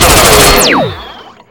rifle1.wav